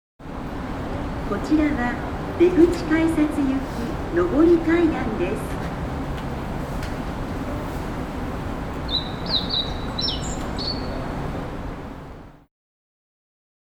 機械のモーター音やアナウンス、発車の合図......。地下鉄、都電荒川線、都営バスのそれぞれの場所でしか聞くことができない音を収録しました。
第4回地下鉄大江戸線「音声誘導チャイム」
第4回 地下鉄大江戸線「音声誘導チャイム」 駅のバリアフリー化の一環として配されている、プラットホーム上の階段の音声案内。門前仲町駅では、島式ホームを意味する「ホオジロ」の鳴き声を流しています。